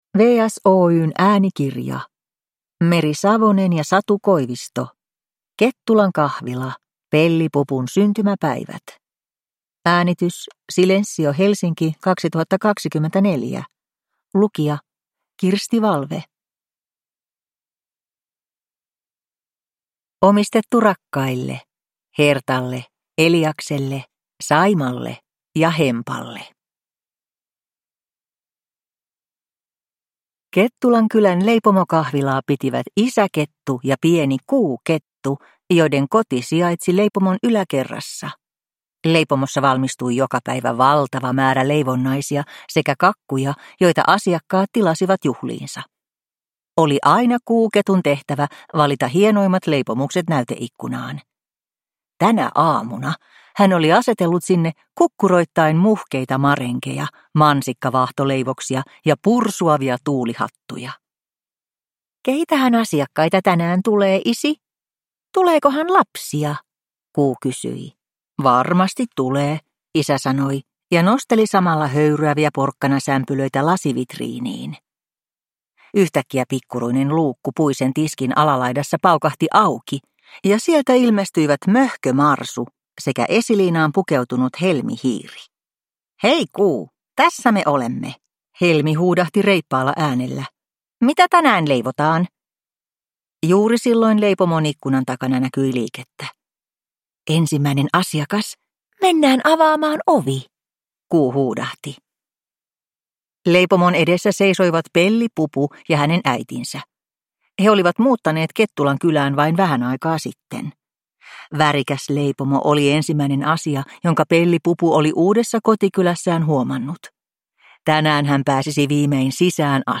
Kettulan kahvila: Pelli-pupun syntymäpäivät – Ljudbok